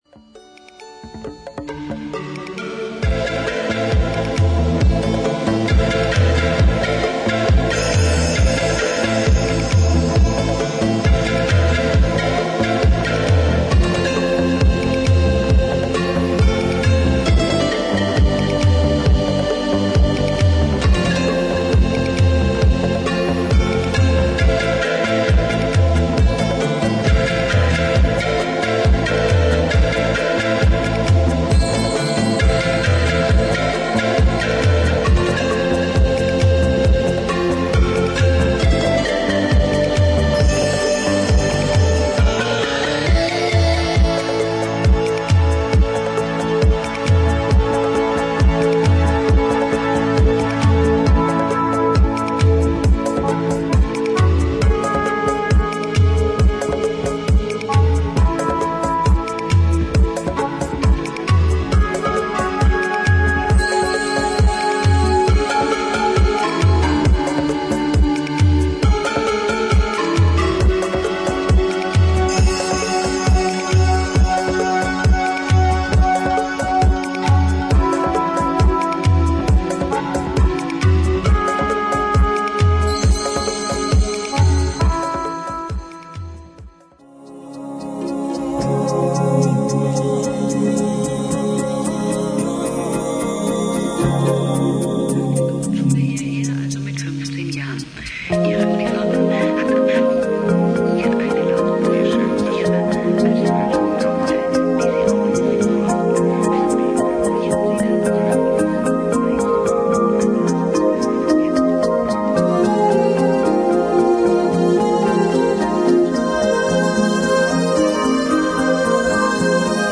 琴や尺八といった和楽器を用い、オリエンタルな雰囲気を演出した
ミニマルなシンセのリフと、ストリングスの絡みが気持ち良い
パーカッシブでコズミックな展開を見せる